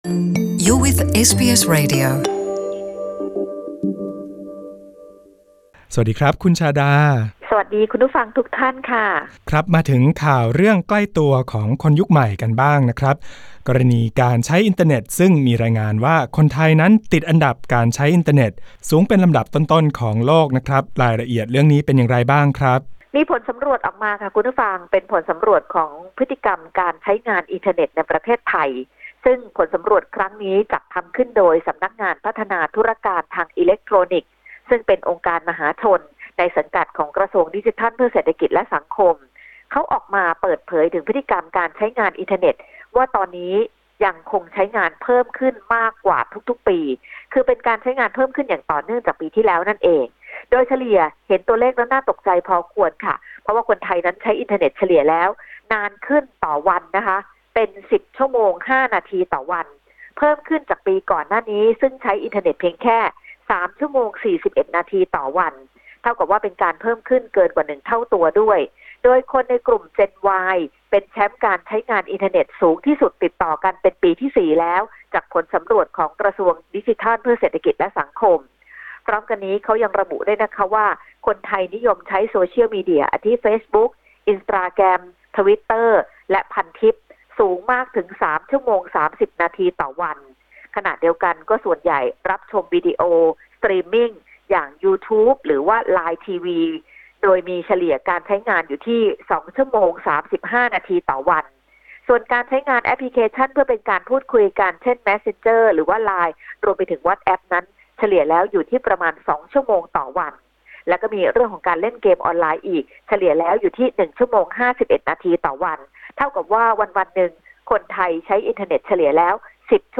รายงานข่าวสายตรงจากเมืองไทย 26 ก.ค. 2018